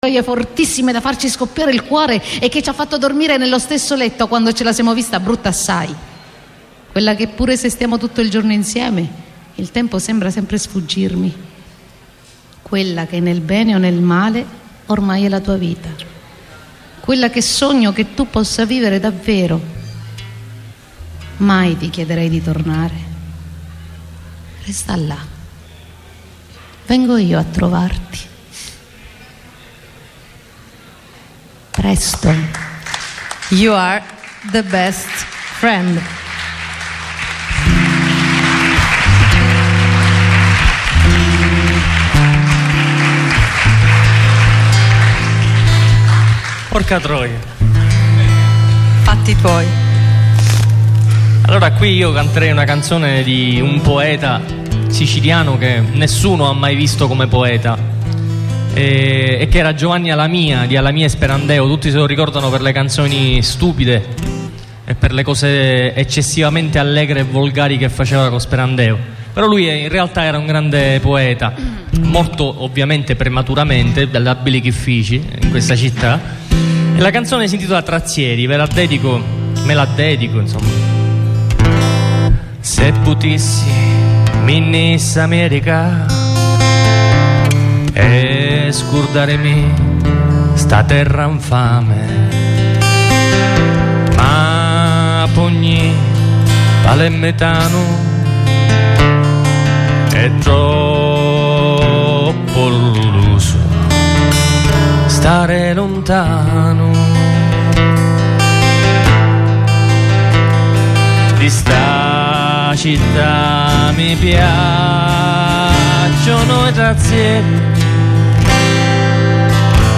Inoltre è disponibile in due parti l’audio della diretta svoltasi su Radio Time .